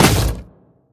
biter-roar-behemoth-4.ogg